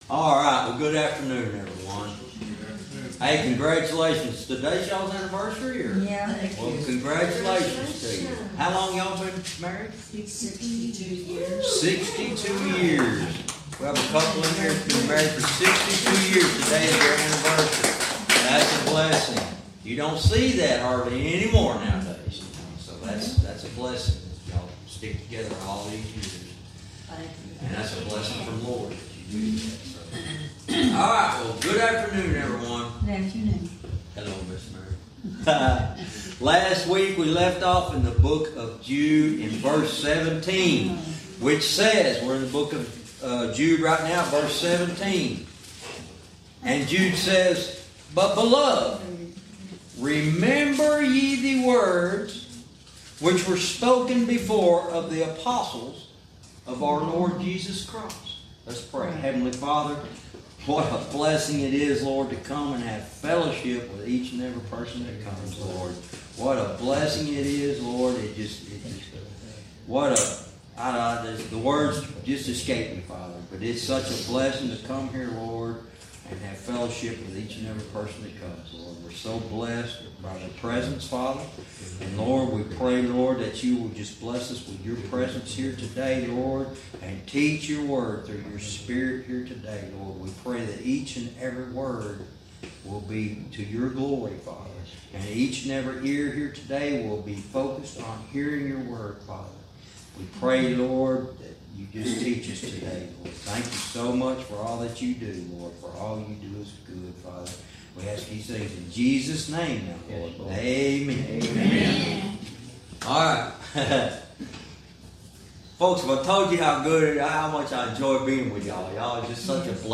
Verse by verse teaching - Jude lesson 76 verse 17